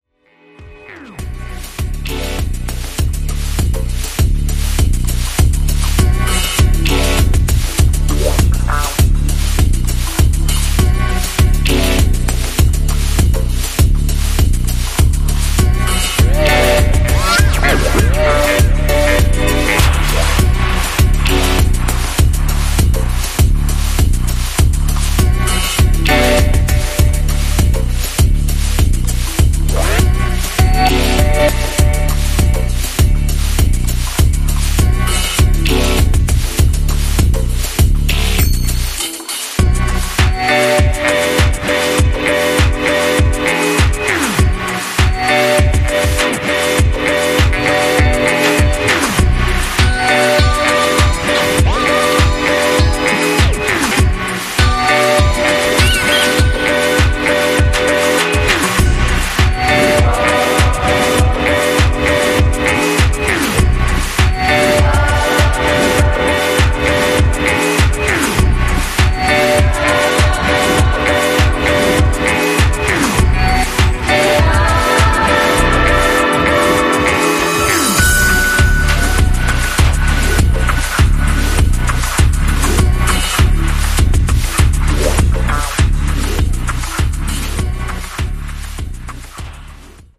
Disco / Balearic